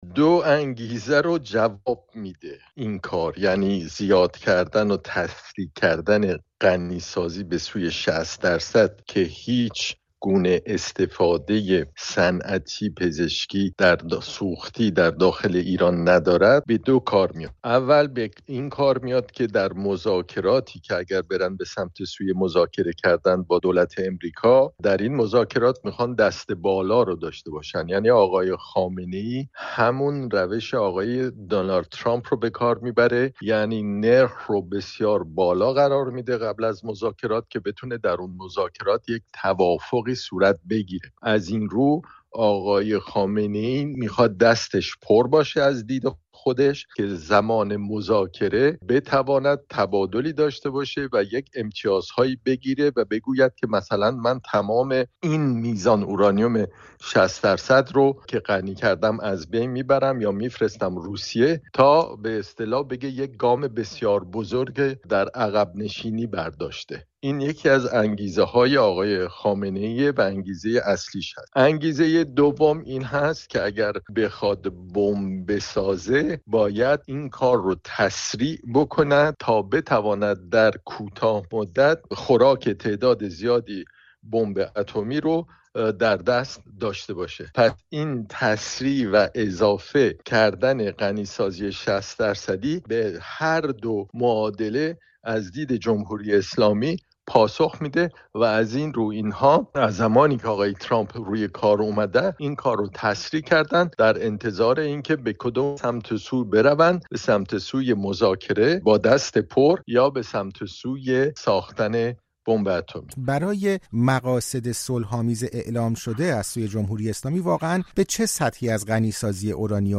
خبرها و گزارش‌ها